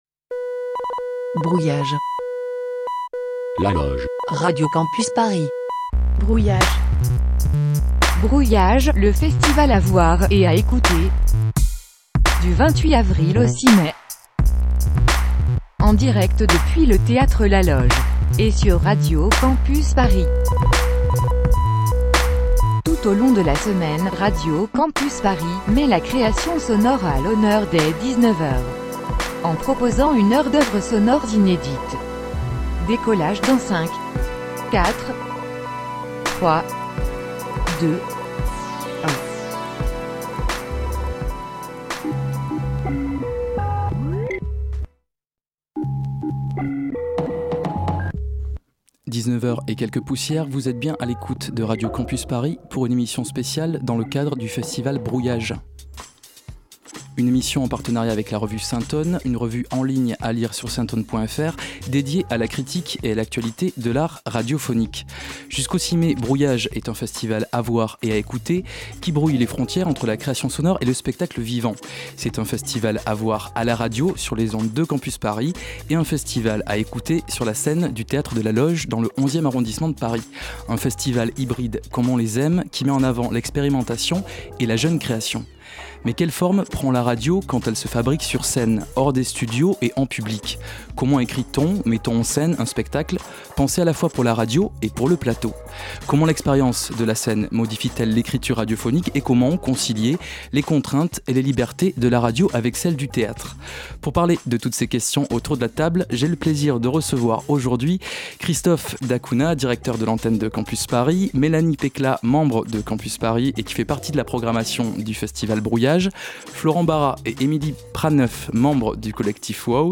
Une émission spéciale dans le cadre du festival Brouillage en partenariat avec la revue en ligne Syntone, dédiée à la critique et à l'actualité de l'art radiophonique.